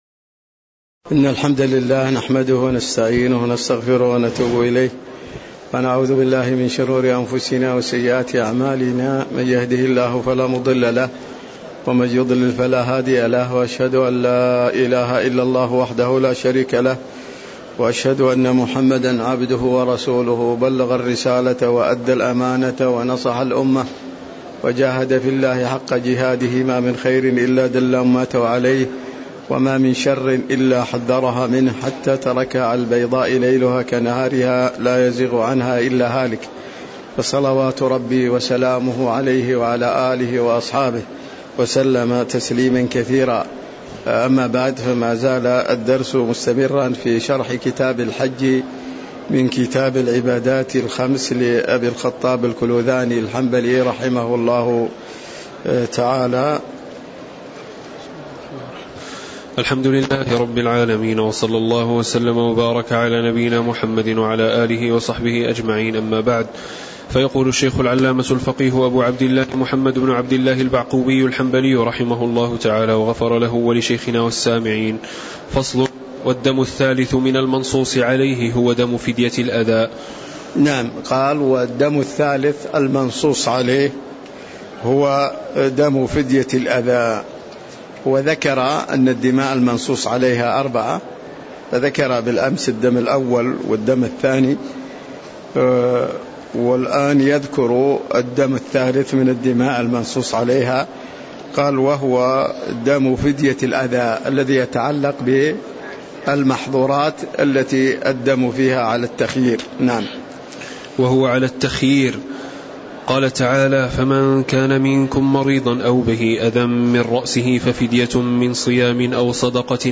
تاريخ النشر ٤ ذو الحجة ١٤٤٤ هـ المكان: المسجد النبوي الشيخ